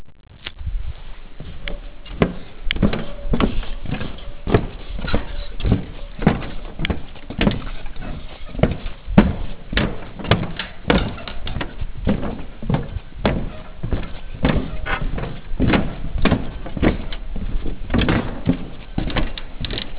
hangfelvétel is, nagyon bíztatón nyikorgott az egész.